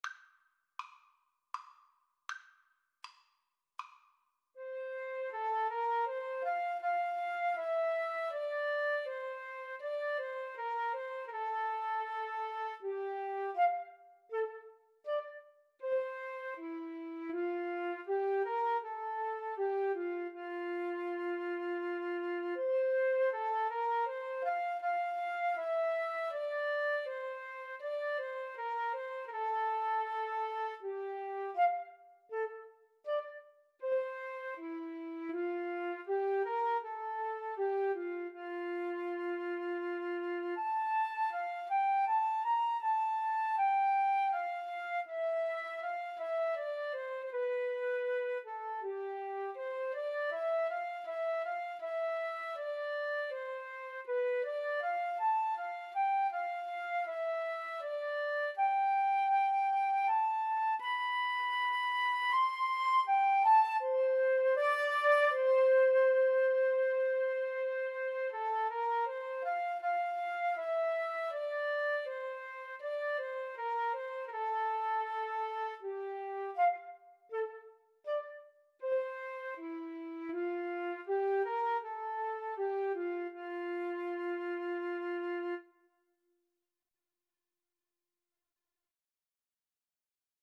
Andante
F major (Sounding Pitch) (View more F major Music for Flute-Cello Duet )
3/4 (View more 3/4 Music)
Classical (View more Classical Flute-Cello Duet Music)